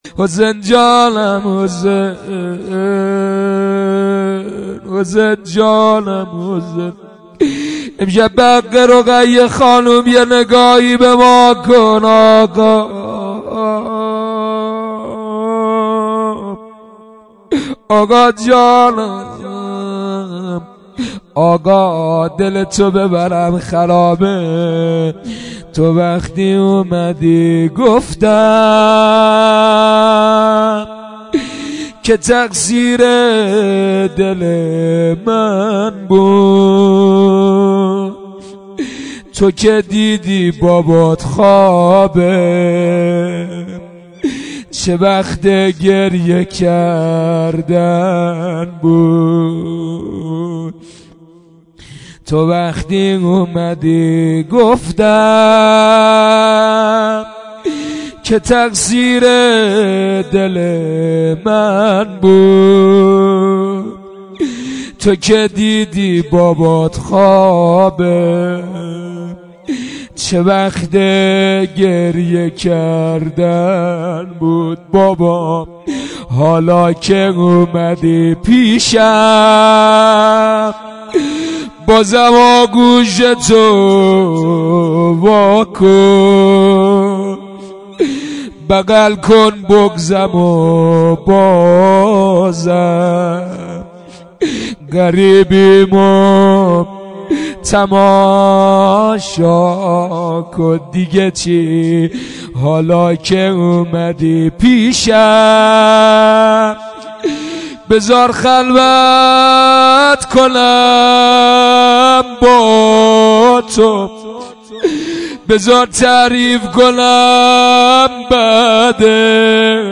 روضه حضرت رقیه (ع)